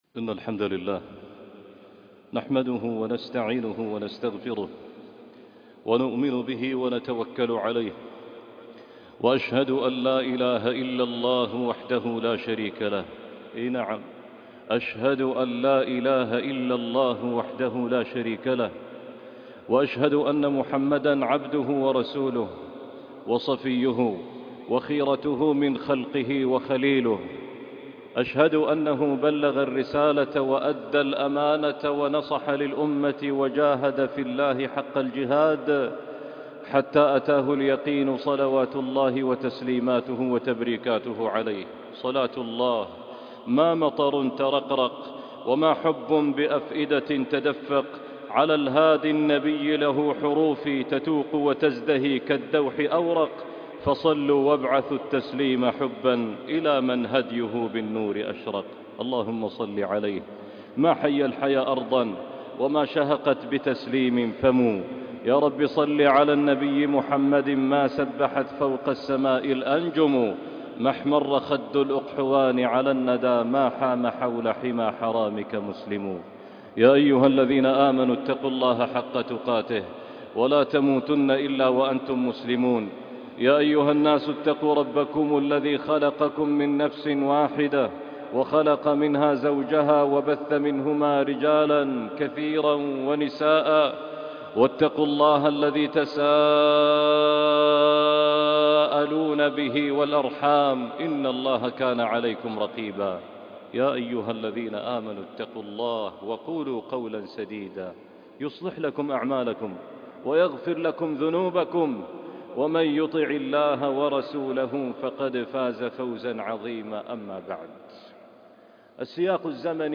رحلة عبر الزمن - خطبة وصلاة الجمعة